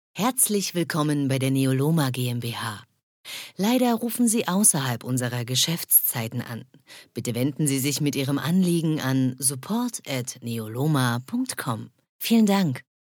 Sprechprobe: eLearning (Muttersprache):
Anrufbeantworter Neoloma GmbH.mp3